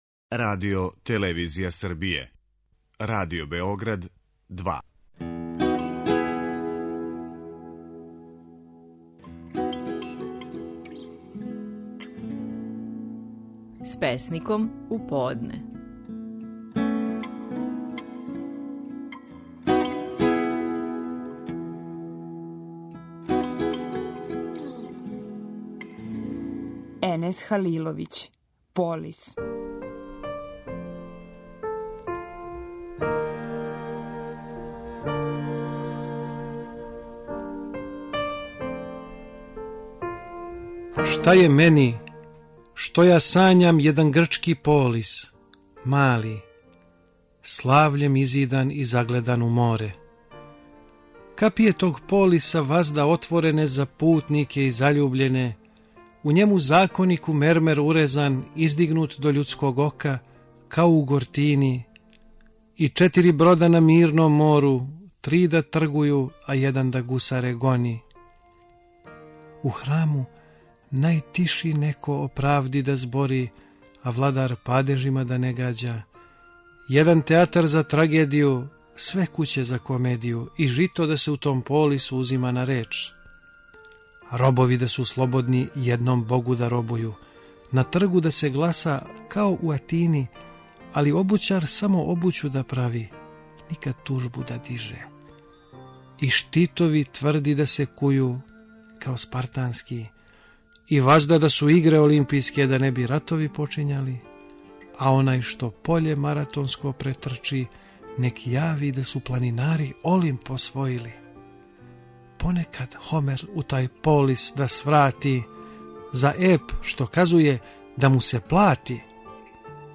Стихови наших најпознатијих песника, у интерпретацији аутора.
Енес Халиловић говори стихове песме „Полис".